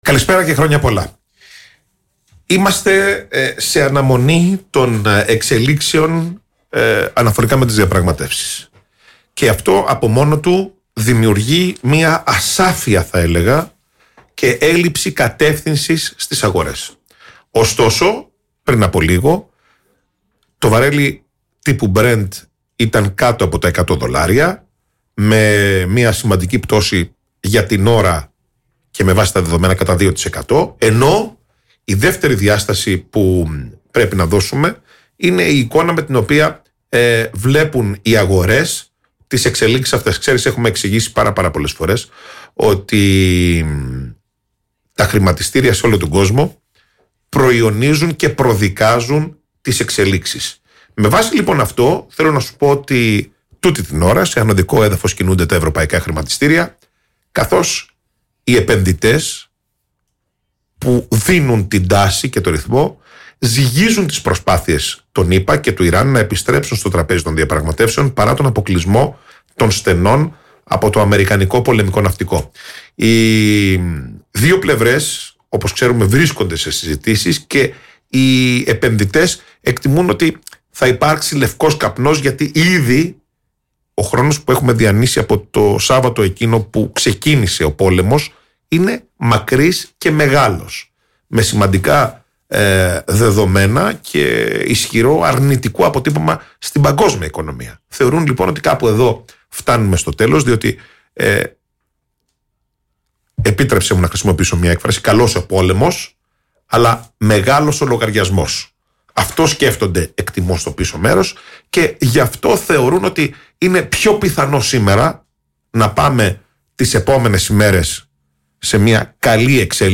στο κεντρικό δελτίο ειδήσεων στον Politica 89,8